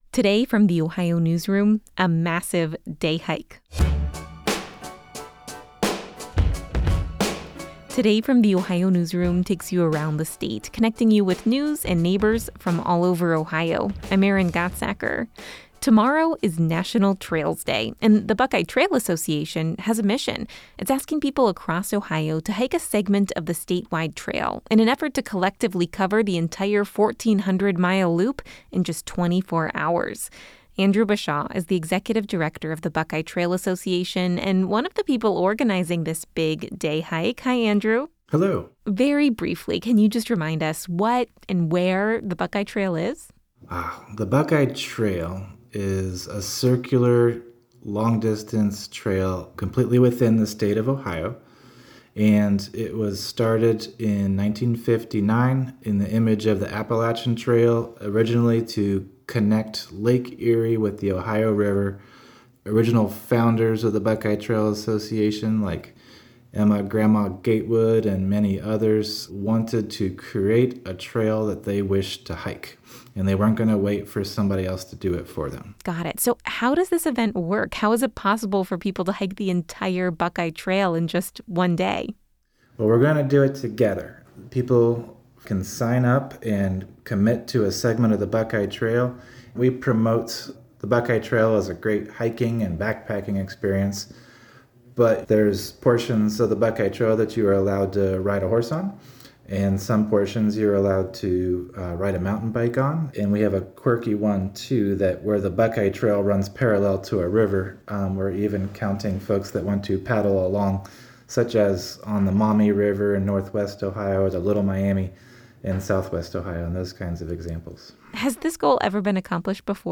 This interview has been edited lightly for brevity and clarity.